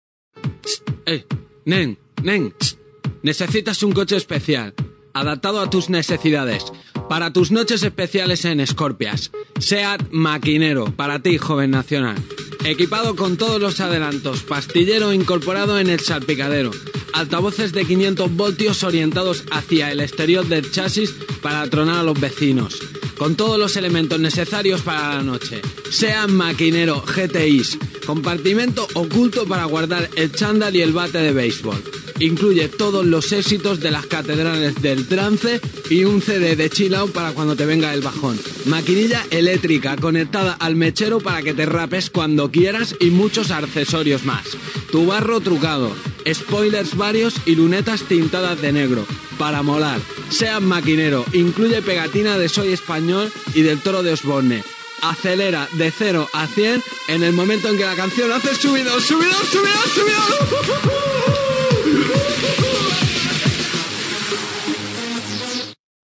Anunci “Seat makinero”.
Entreteniment